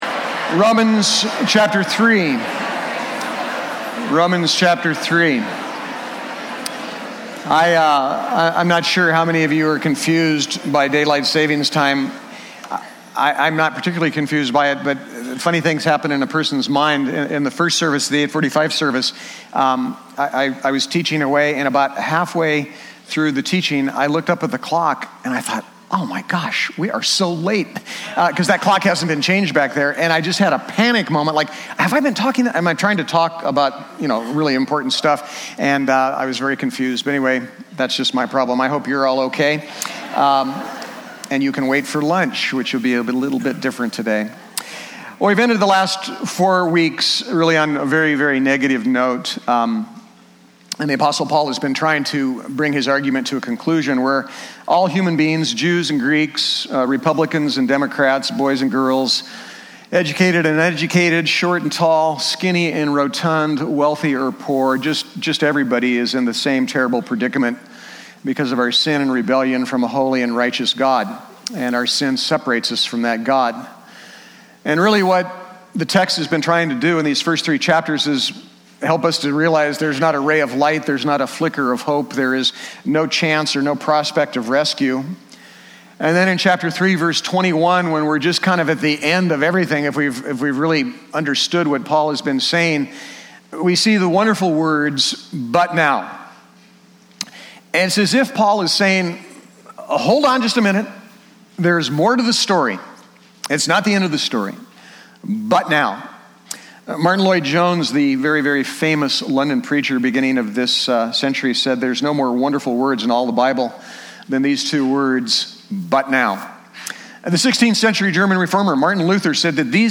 Bible Text: Romans 3:21-31 | Preacher